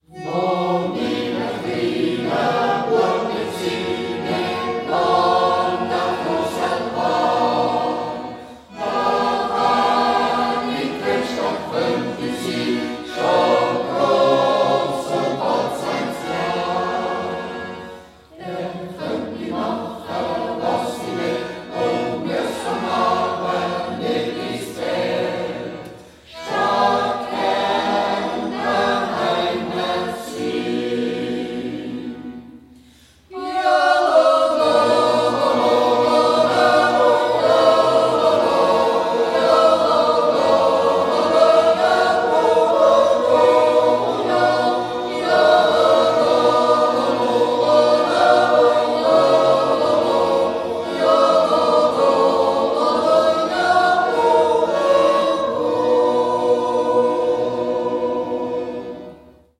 ニューグラールス・ヨーデルクラブ
New Glarus Jodelklub